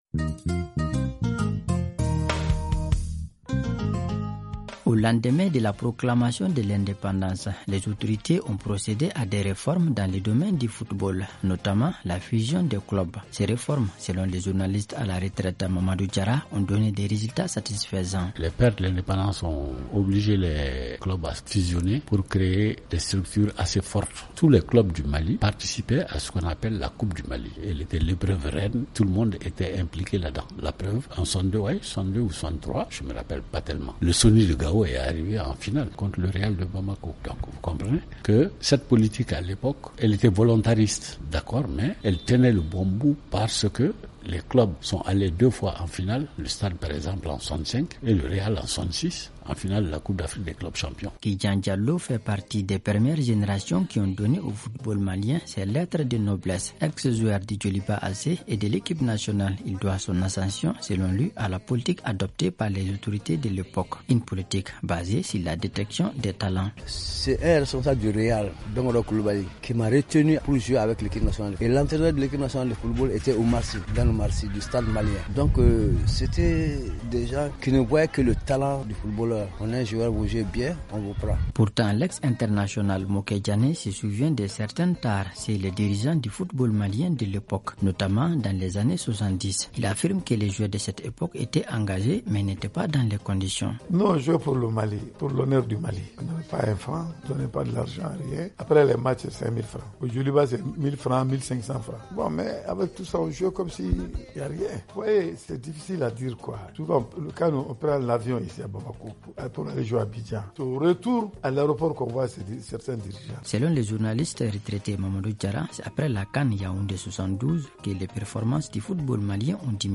Aux premières heures de l’indépendance dans les années 60, les dirigeants du Mali avaient adopté une politique dans le domaine du sport notamment le football. Il s’agissait de développer la pratique de cette discipline et de bien défendre les couleurs du Mali dans les compétitions internationales, expliquent des anciens joueurs et journalistes sportifs. Ils affirment que malgré le manque de moyens, les footballeurs maliens ont honoré le pays sur tout le continent.